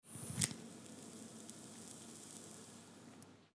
Конопля тлеет внутри сигареты при затяжке с характерным звуком